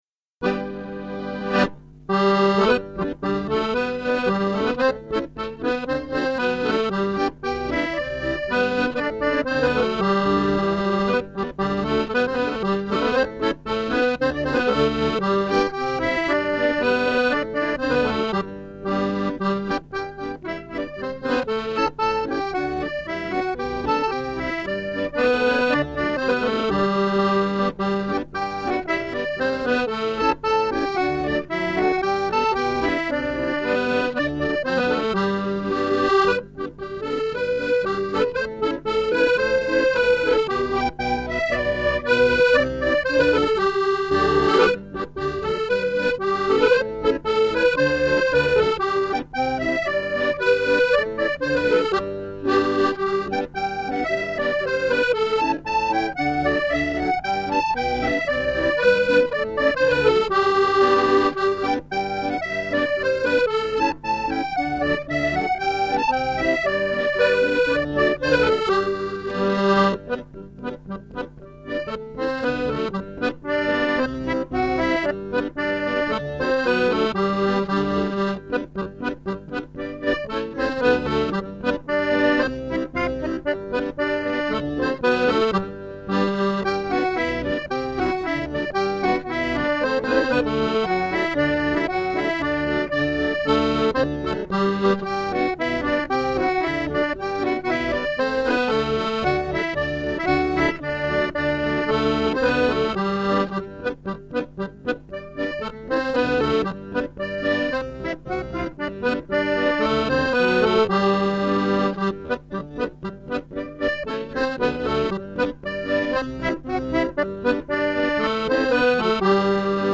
Music - 32 bar reels or jigs